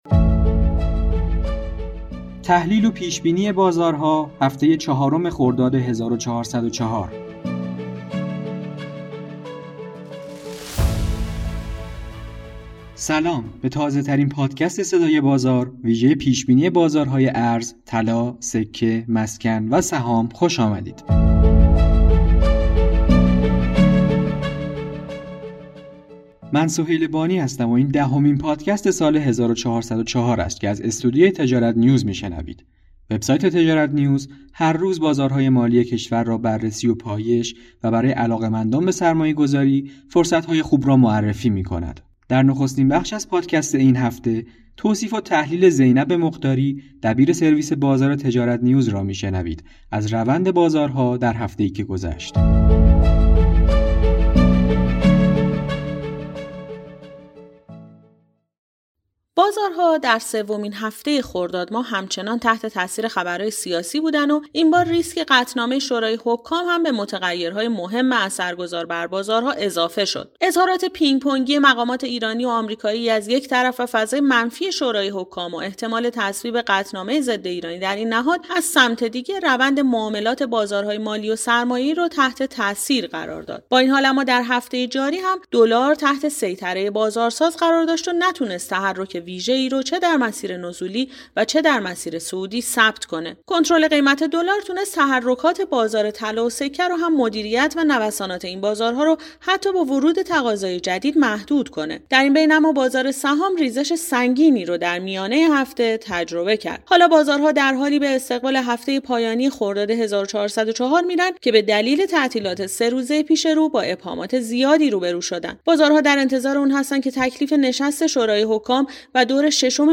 به گزارش تجارت نیوز، این دهمین پادکست سال 1404 است که از استودیوی تجارت‌نیوز می‌شنوید.